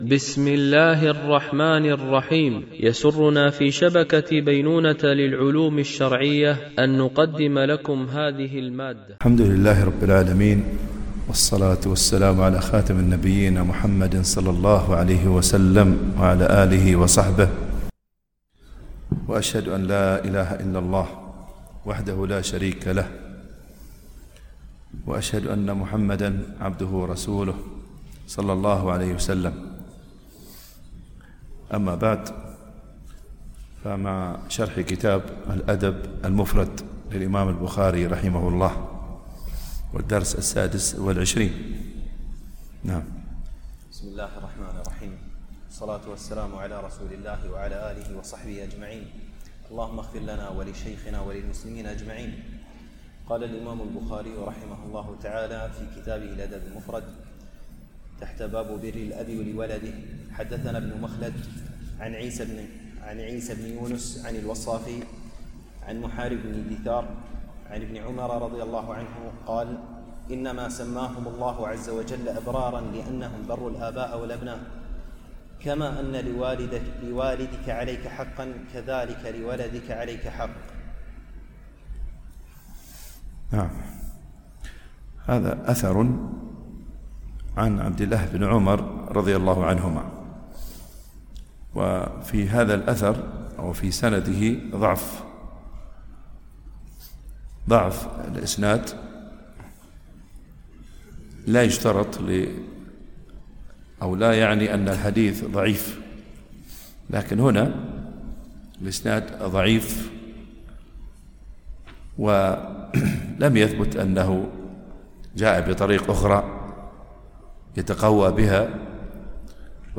الشرح الثاني للأدب المفرد للبخاري - الدرس 26 ( الحديث 94 -100 )